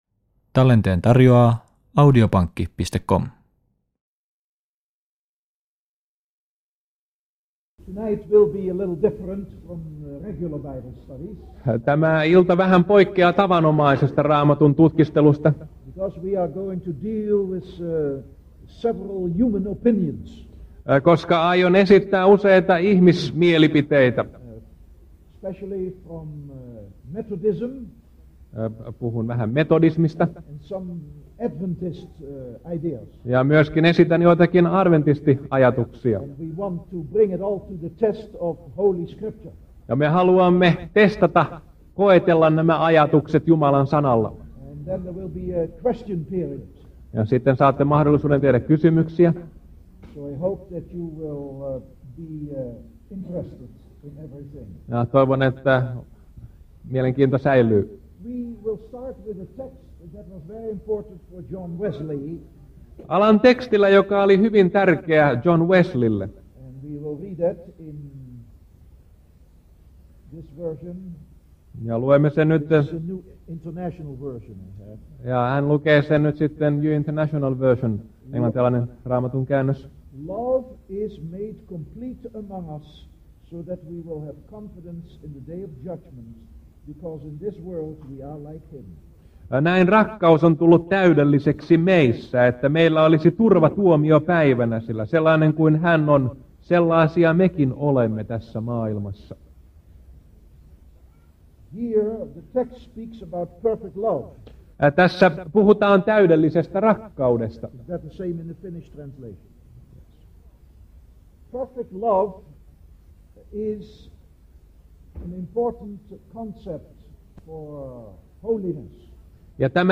Kallioniemessä elokuussa 1988